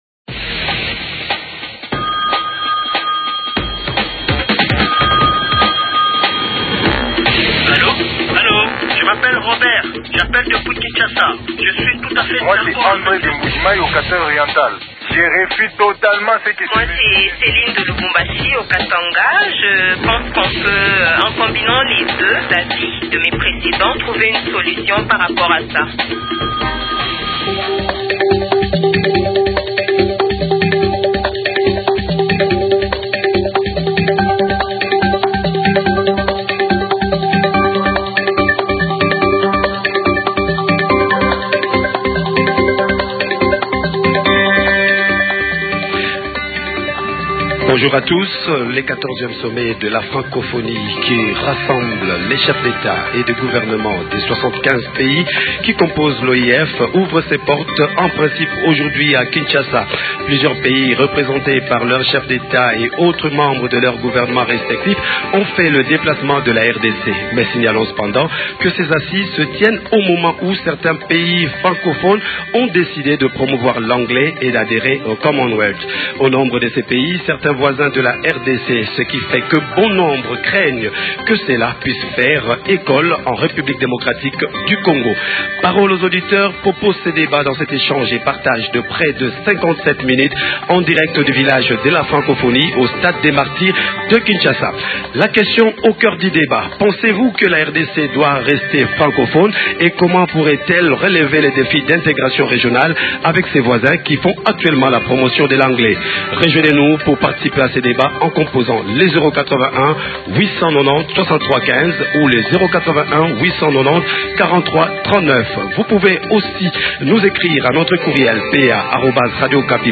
Parole aux auditeurs sera en direct du village de la francophonie pour une émission spéciale en marge du XIVè sommet qui se tient à Kinshasa du 12 au 14 octobre.